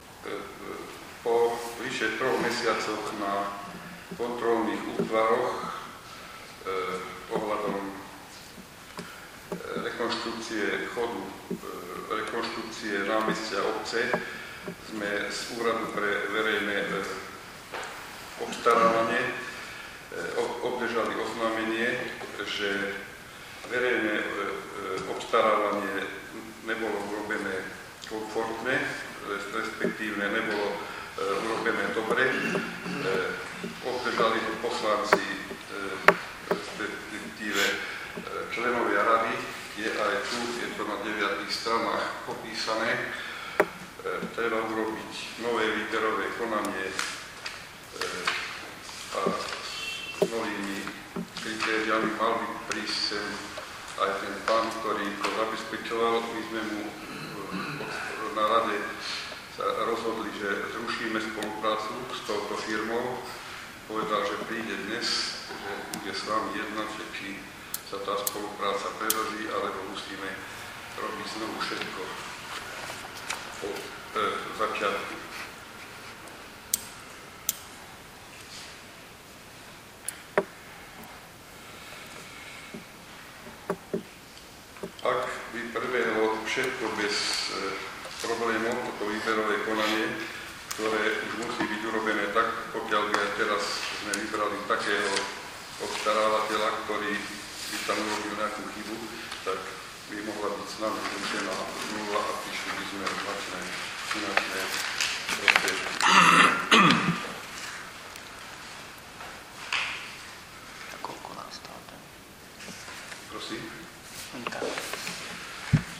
Na zasadnutí obecného zastupiteľstva (6. júna) boli tejto téme venované približne dve minúty. Časť, v ktorej starosta obce Miloš Gallo Barnák (SDKÚ-DS, SaS) informuje o rozhodnutí ÚVO, si vypočujte